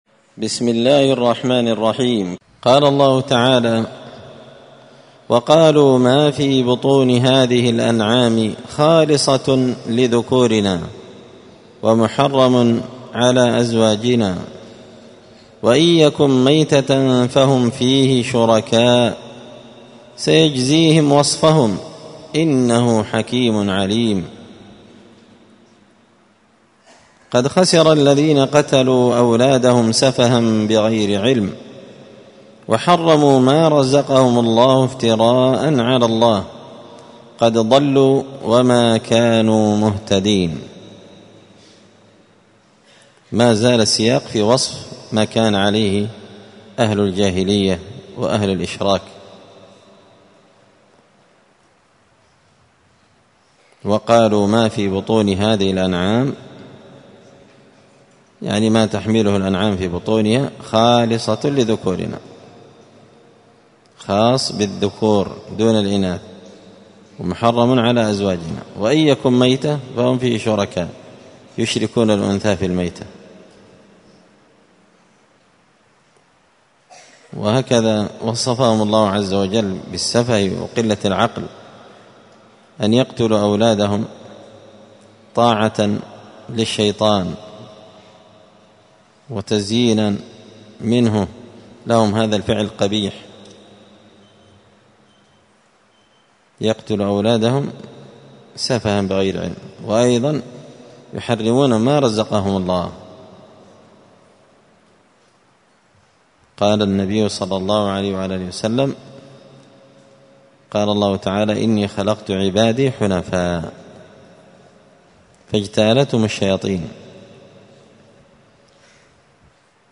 الدروس اليومية
مسجد الفرقان قشن_المهرة_اليمن